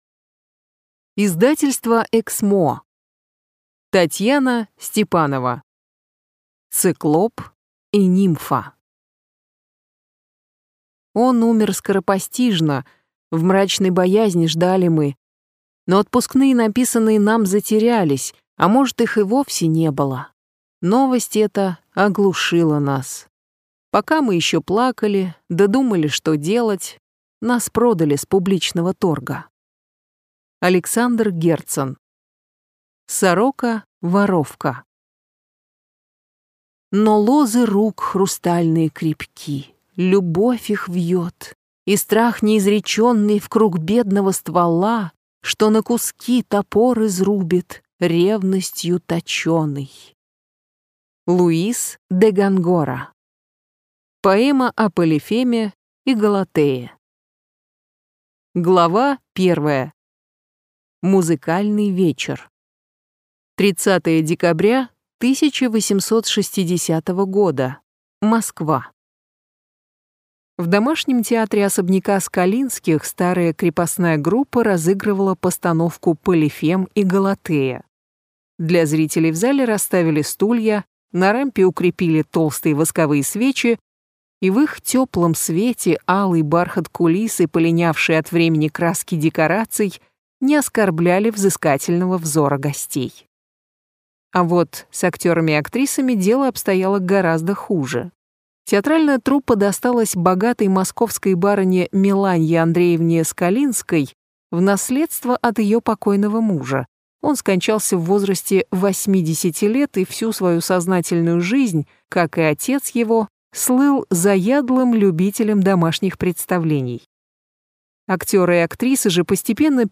Аудиокнига Циклоп и нимфа - купить, скачать и слушать онлайн | КнигоПоиск